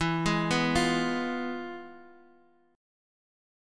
Fdim/E chord
F-Diminished-E-x,x,2,1,0,1-8.m4a